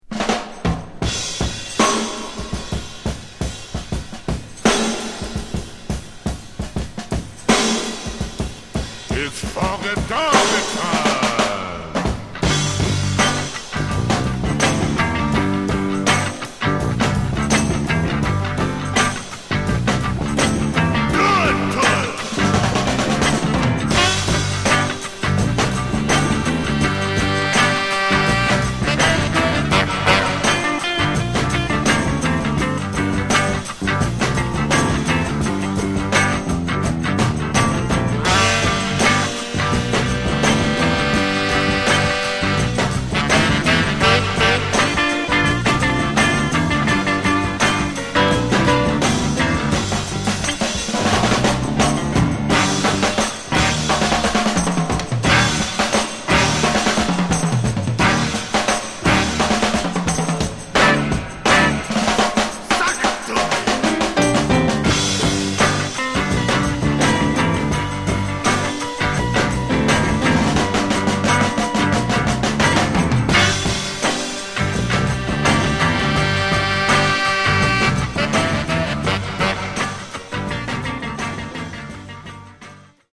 Genre: Soul Instrumentals